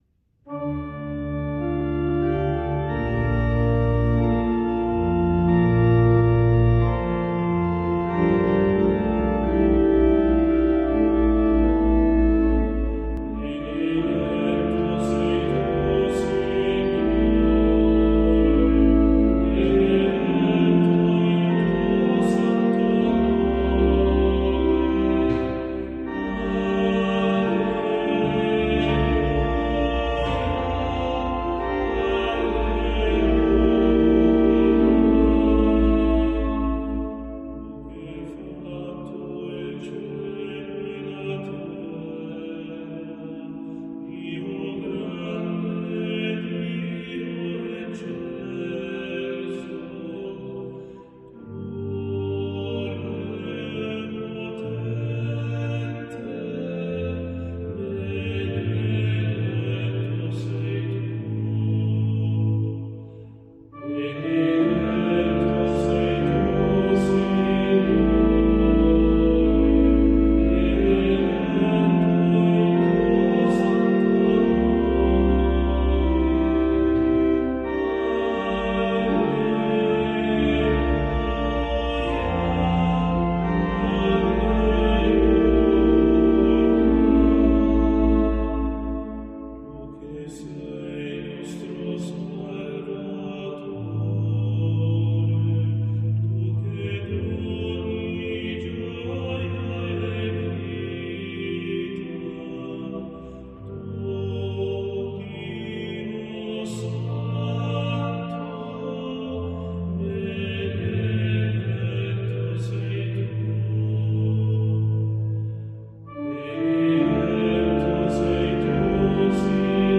Siamo dinanzi a una lode che è professione di fede al tempo stesso: il nome di Dio è benedetto perché è grande, immenso, di una bontà disarmante. L’ariosità della linea melodica lo rende cantabile da tutta l’assemblea (riservando le strofe a un solista o a un piccolo gruppo).